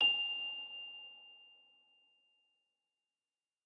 celesta1_15.ogg